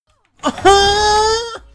Play, download and share TheMoan original sound button!!!!
themoan-online-audio-converter.mp3